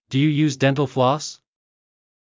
ﾄﾞｩ ﾕｰ ﾕｰｽﾞ ﾃﾞﾝﾀﾙ ﾌﾛｽ